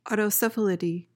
PRONUNCIATION:
(ah-to-suh-FAL-i-tee)